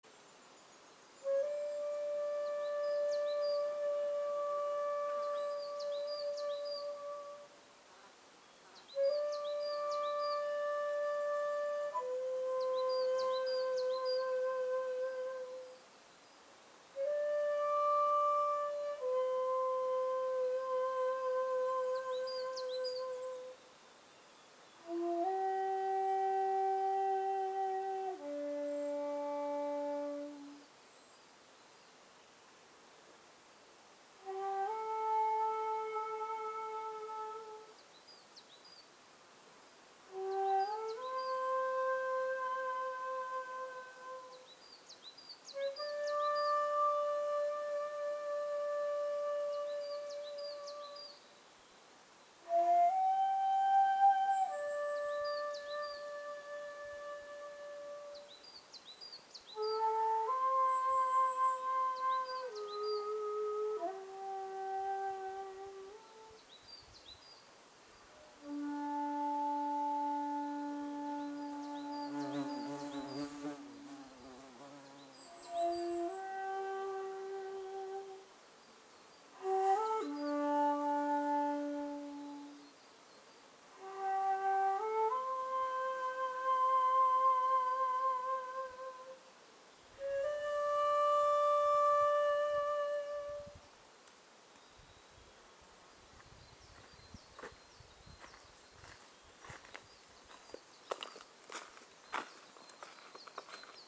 誰もいないので休憩がてら地無し尺八の音出しを始めました。
鳥のさえずりを邪魔しないように1分ほど目をつぶって吹いていますといきなり「ブーーーン」という音と共に喉をアブに噛まれました。